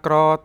3-s1-kraa-careful.wav